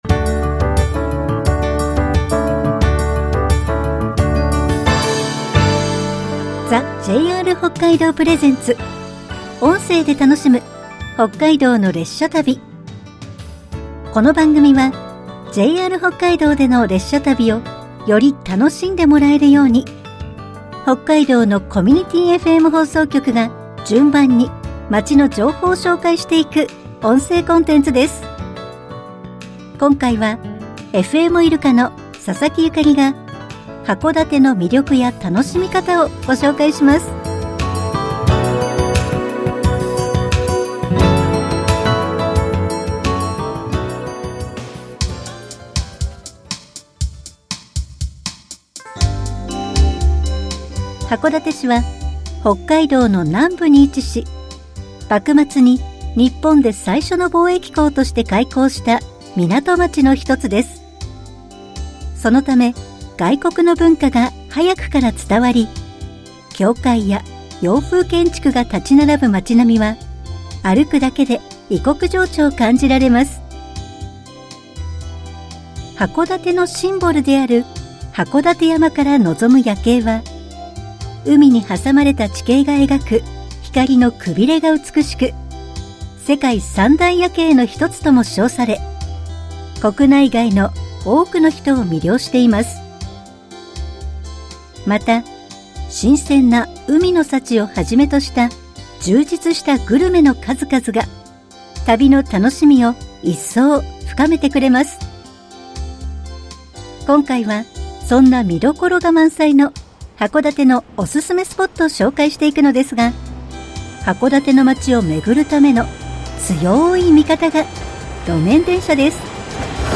制作局 FMいるか 公式サイト 1992年12月24日に開局した日本で最初のコミュニティFM放送局。